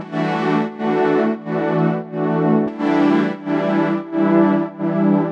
GnS_Pad-MiscB1:4_90-E.wav